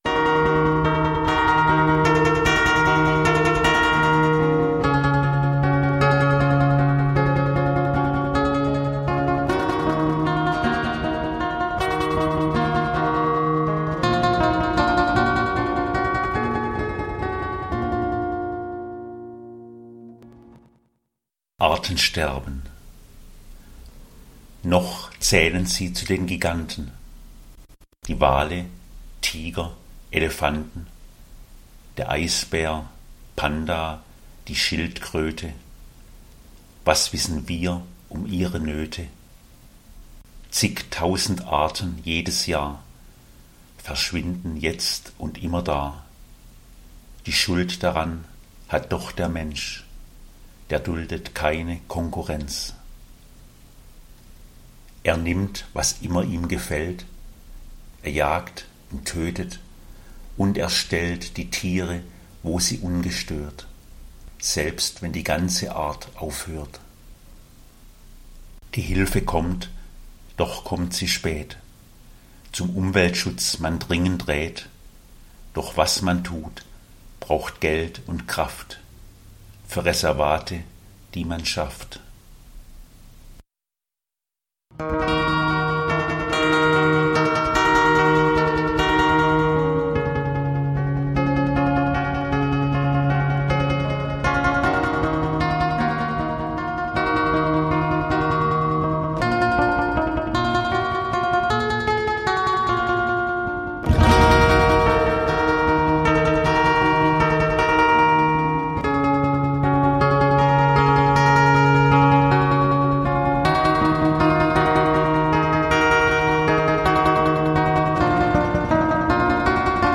Rezitation